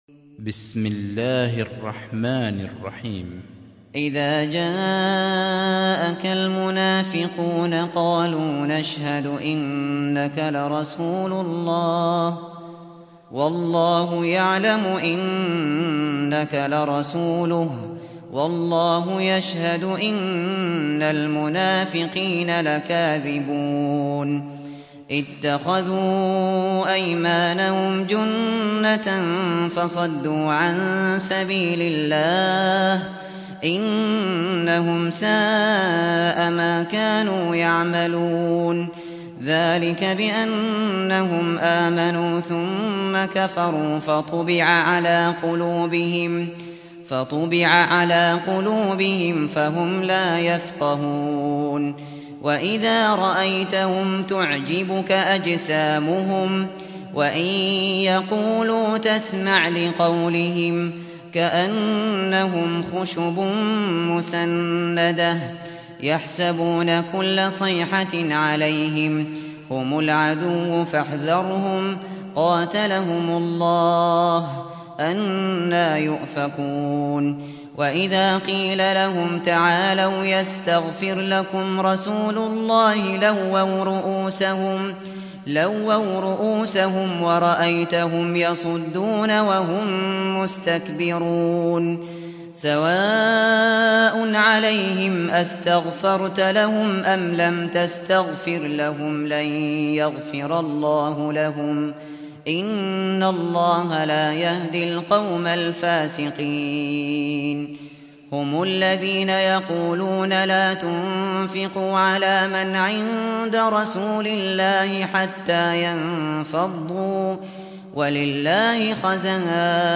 سُورَةُ المُنَافِقُونَ بصوت الشيخ ابو بكر الشاطري